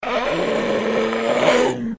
c_hyena_bat2.wav